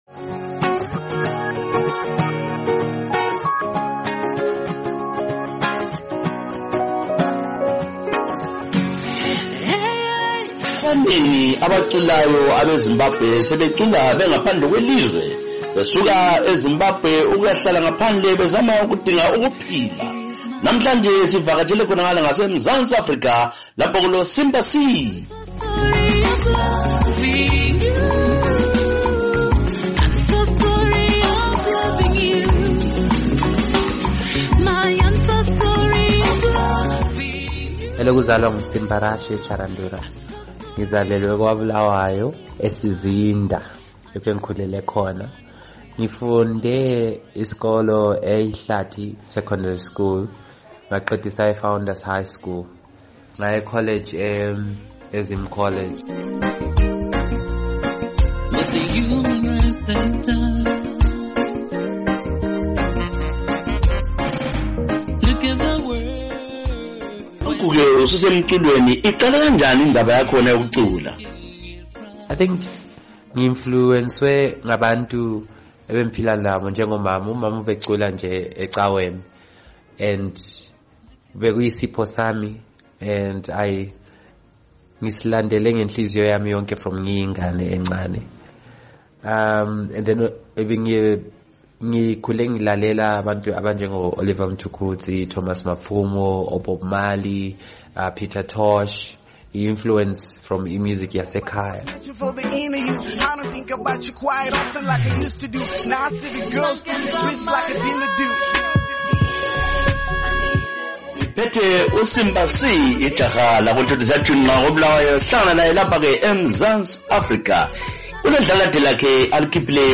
ingoma zeAfro Soul Reggae